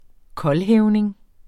Udtale [ ˈkʌlˌhεːwneŋ ]